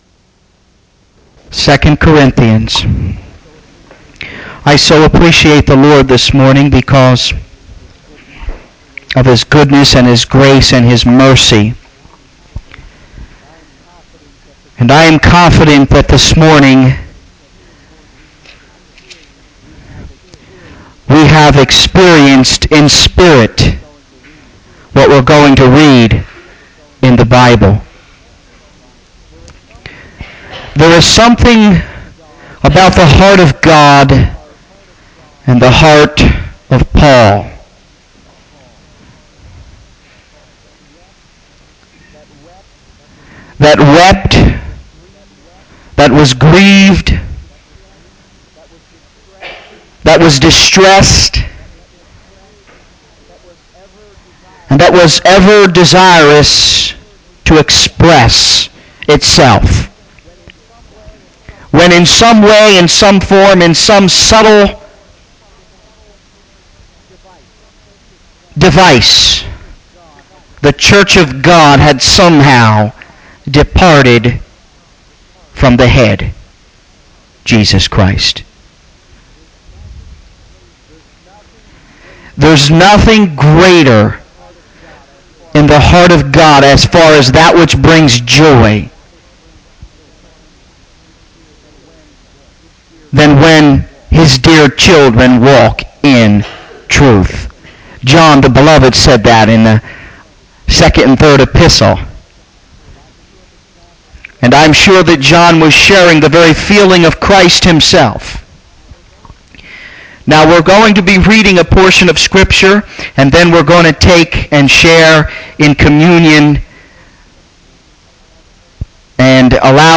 The sermon culminates in a call for self-examination and a return to faith, underscoring the need for unity and maturity in Christ. Ultimately, the message is a reminder of God's longing for His people to walk in truth and love.